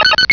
pokeemerald / sound / direct_sound_samples / cries / togetic.aif
-Replaced the Gen. 1 to 3 cries with BW2 rips.